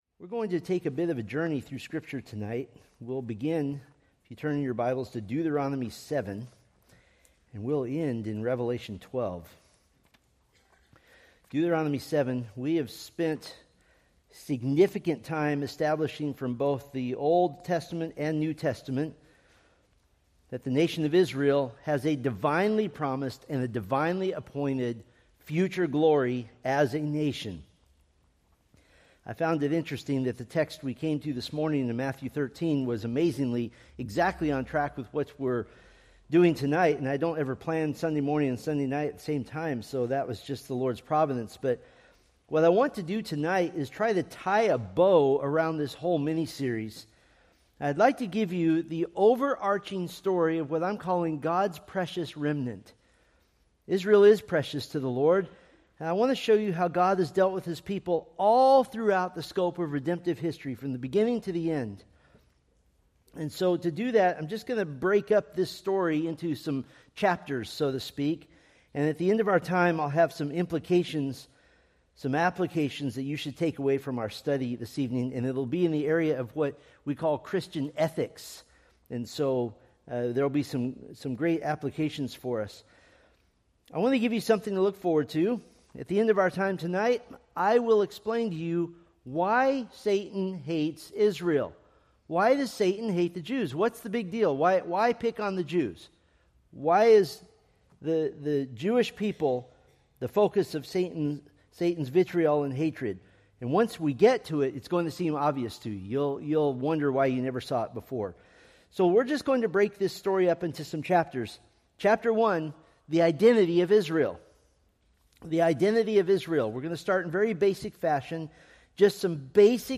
Preached February 1, 2026 from Selected Scriptures